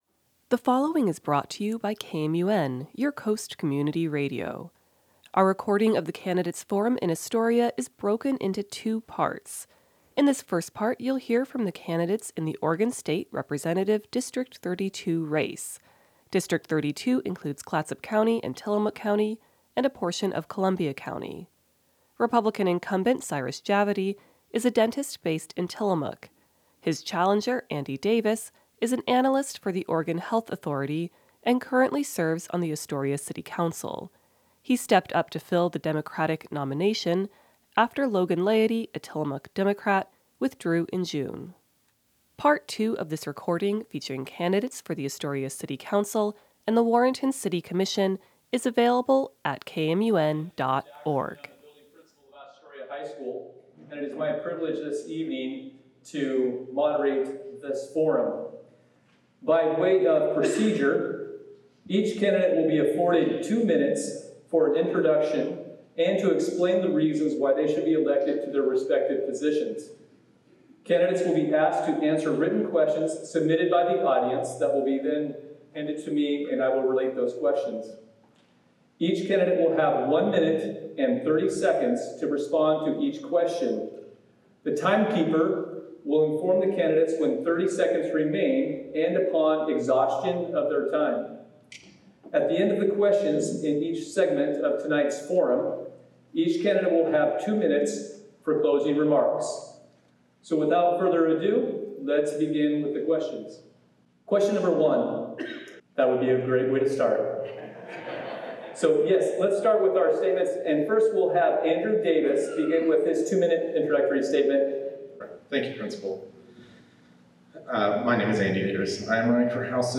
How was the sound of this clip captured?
This program was recorded at Astoria High School on Monday, October 14th, 2024. The forum was organized and hosted by the Astoria AAUW chapter.